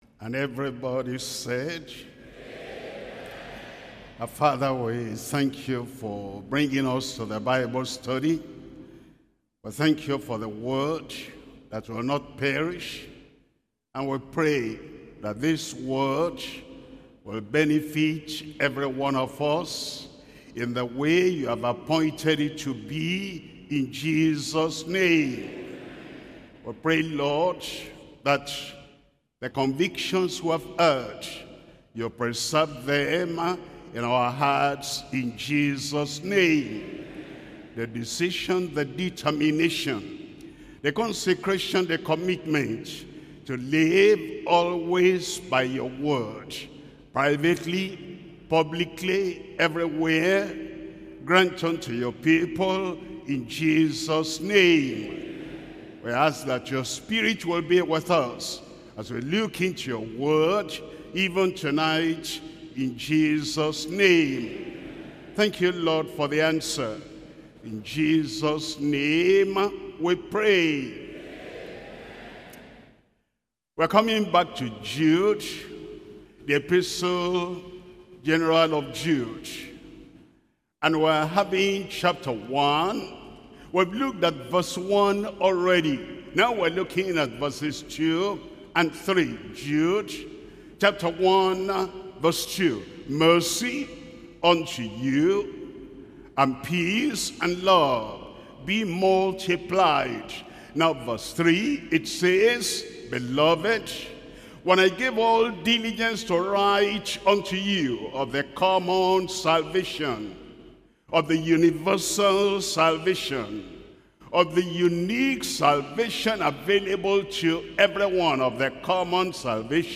SERMONS – Deeper Christian Life Ministry Australia
Bible Study